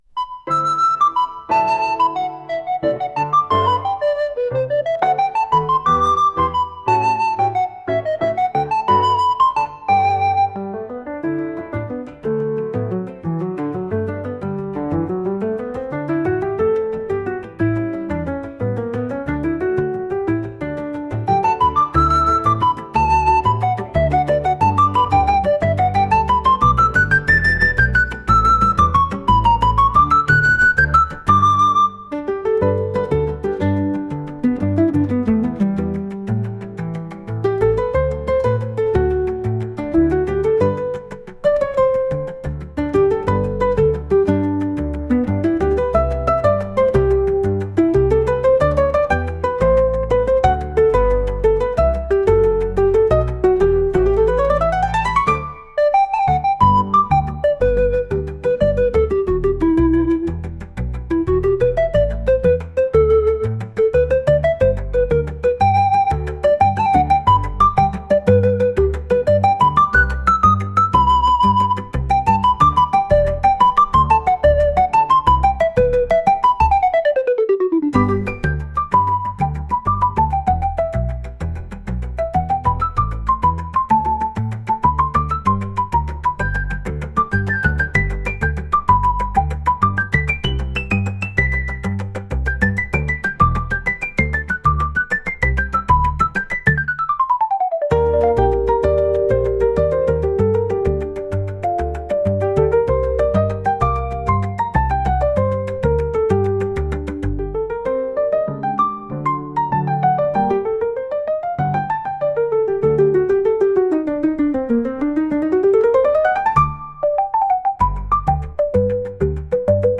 朝の澄んだ空気のような曲と言えなくもない感じです。